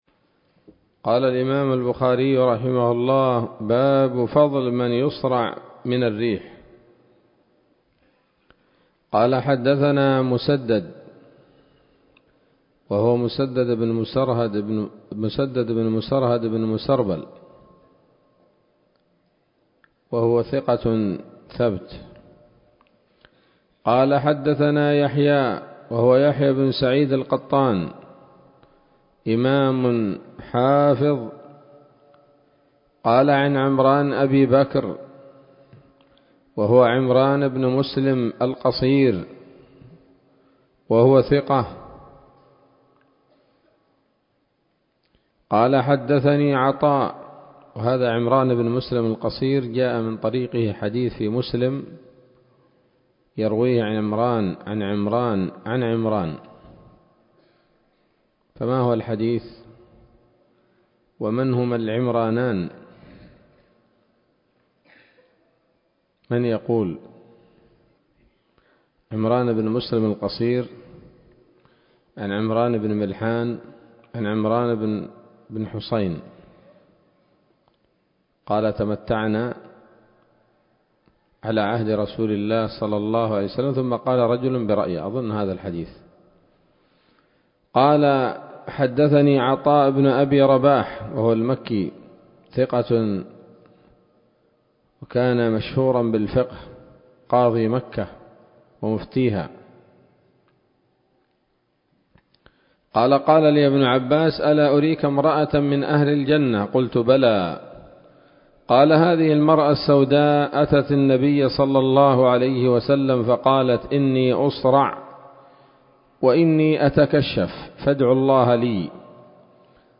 الدرس الخامس من كتاب المرضى من صحيح الإمام البخاري